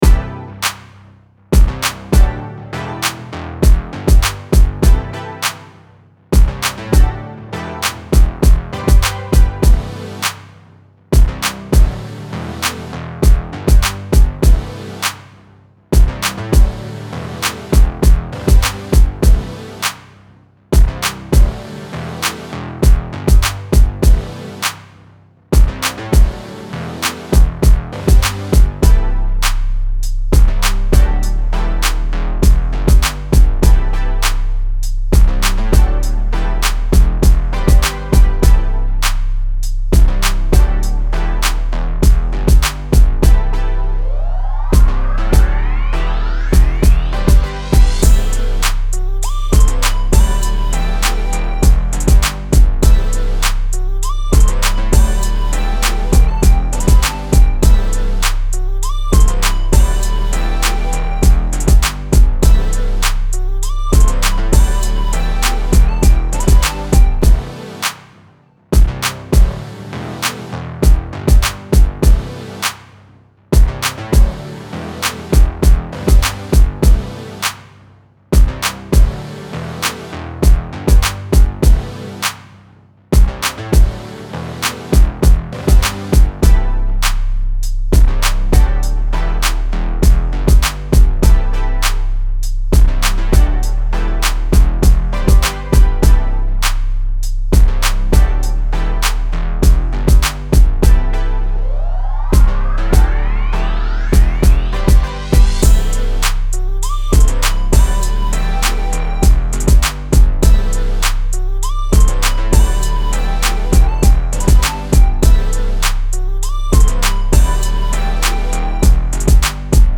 Pop, Dance
E min